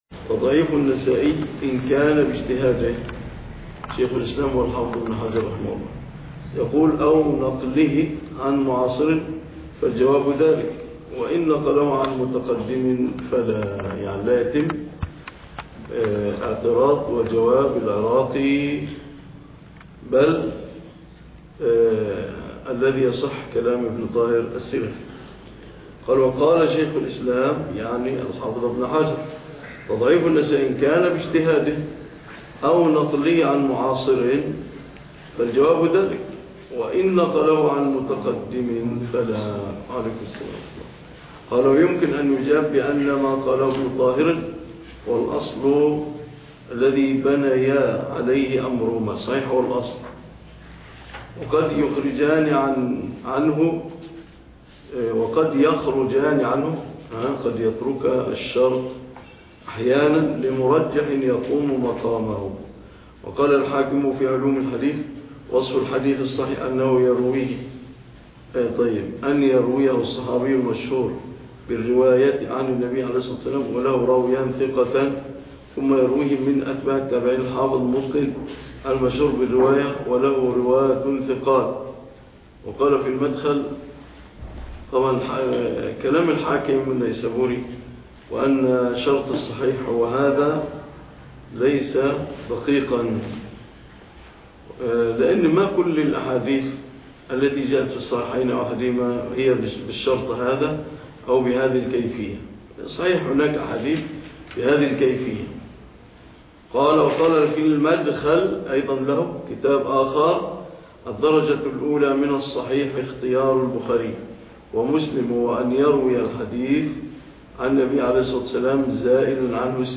عنوان المادة الدرس 020 (شرح كتاب تدريب الراوى )